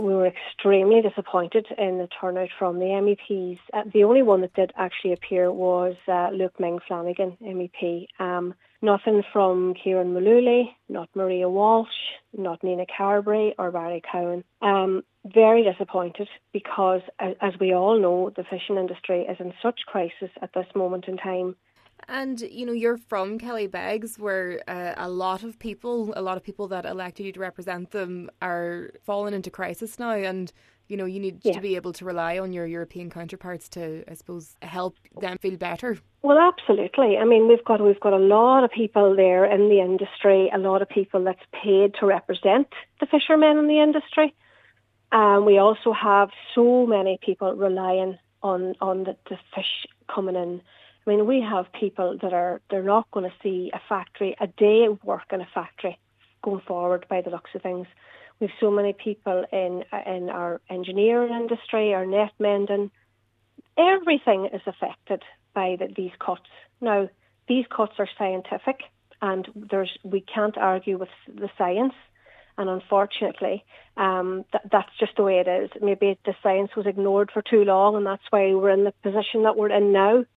Cllr Niamh Kennedy says that the people who are now in crises need better representation in Europe: